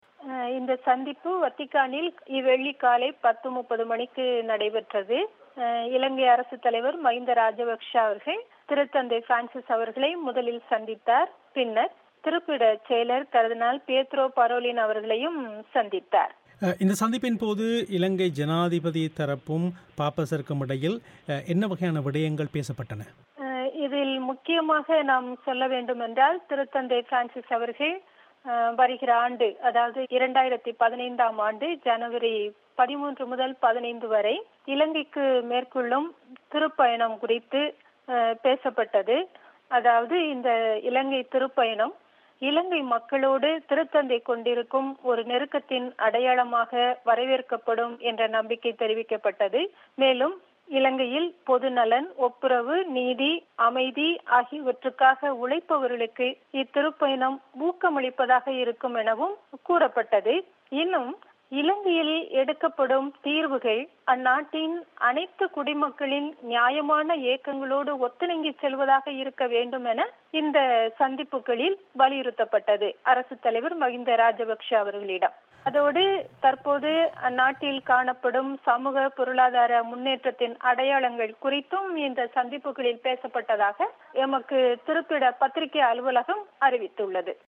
செவ்வி